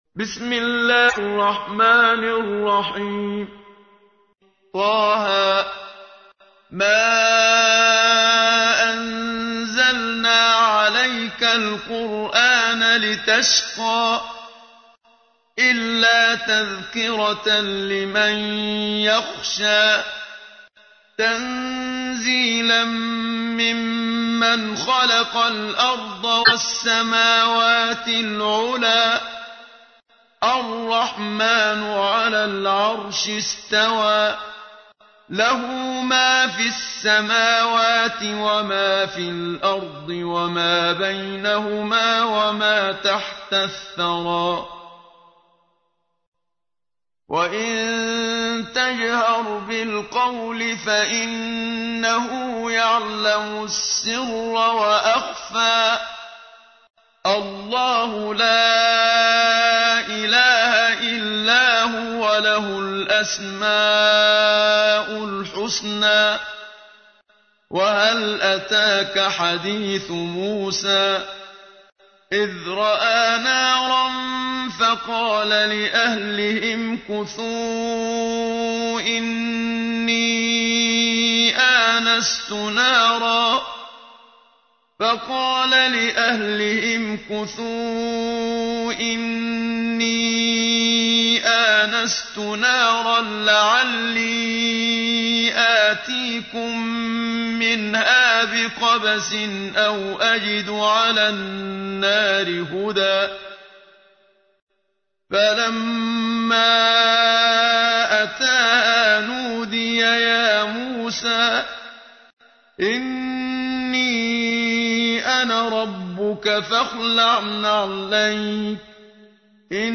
تحميل : 20. سورة طه / القارئ محمد صديق المنشاوي / القرآن الكريم / موقع يا حسين